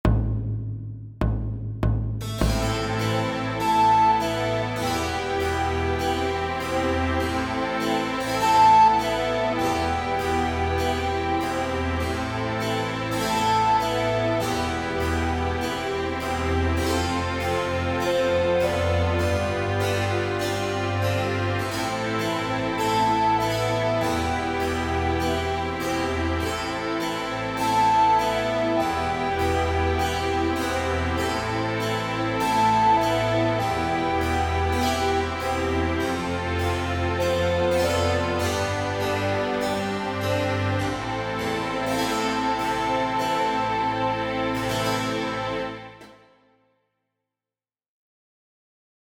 Tonalidade: la eolio; Compás 4/4
base_eolia.mp3